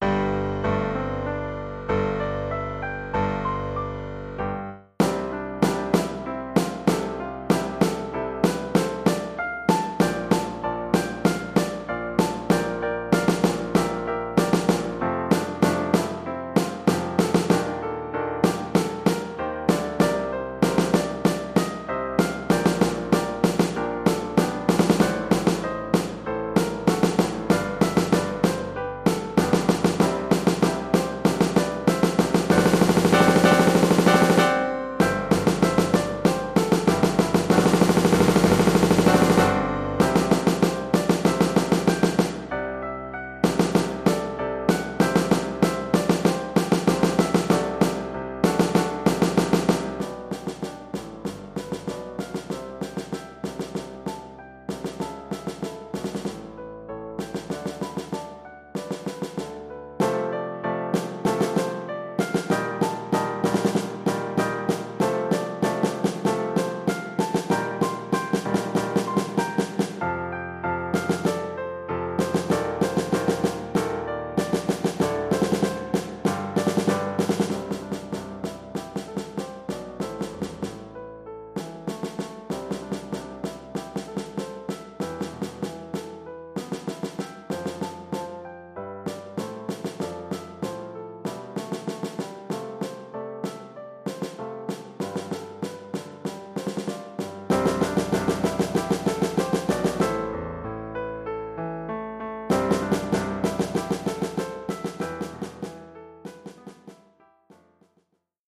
Collection : Percussions
Oeuvre pour caisse claire et piano.